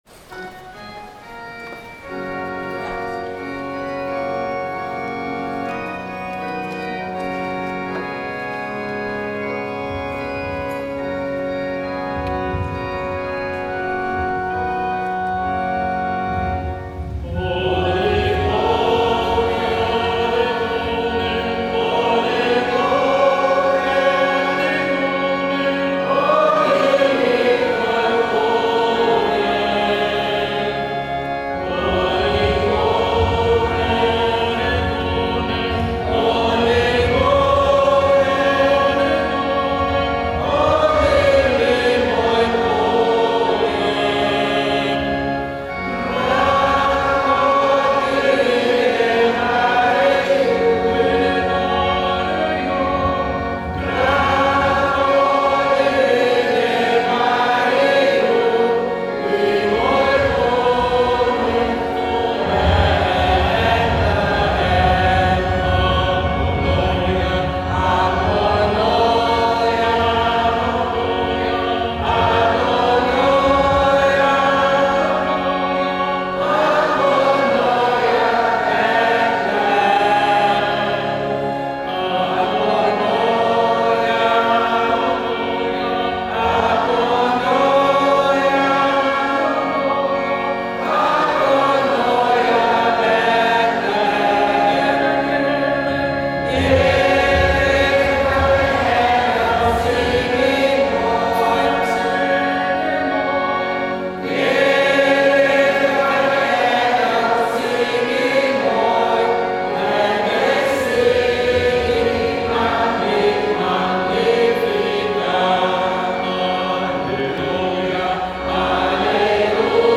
Ik bevind mij in de Kathedrale Basiliek van Sint Petrus en Paulus in het centrum van Paramaribo.
Voor in de kerk zingt een klein koor liederen in het Nederlands en in het Sarantongo en ze worden begeleid door een organist.
Als intermezzo speelt het orgel en zingt het koor met solozang van de in het rood geklede voorgang.
Paramaribo-Goede-Vrijdag-Basiliek.mp3